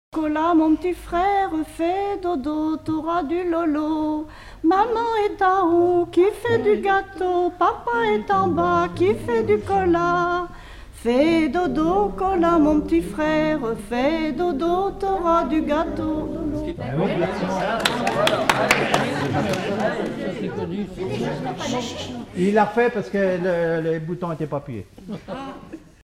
berceuse
Collectif-veillée (1ère prise de son)
Pièce musicale inédite